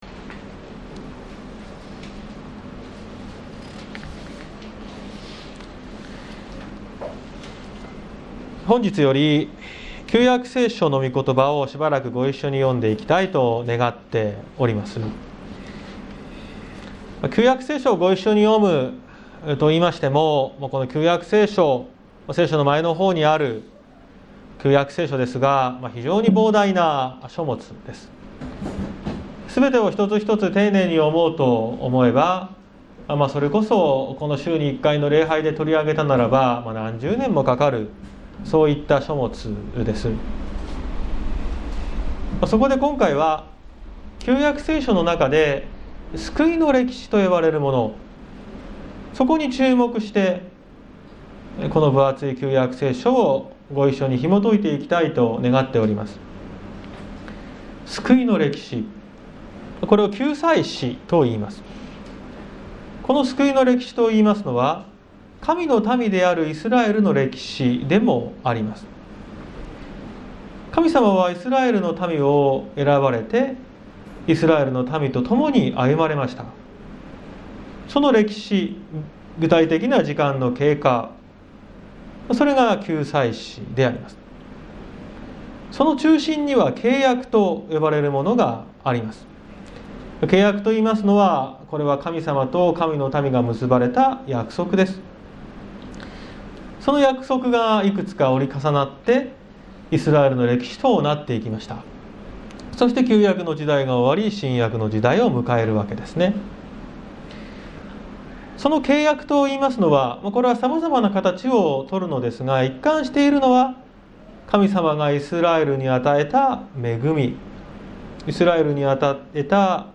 2021年09月05日朝の礼拝「光の勝利」綱島教会
説教アーカイブ。